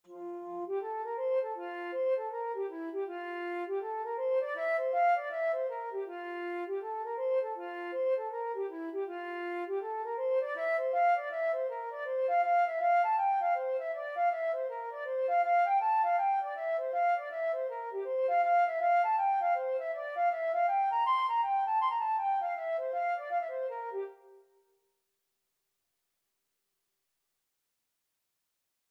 (Irish Folk Song) Flute version
4/4 (View more 4/4 Music)
E5-C7
F major (Sounding Pitch) (View more F major Music for Flute )
Flute  (View more Easy Flute Music)
Traditional (View more Traditional Flute Music)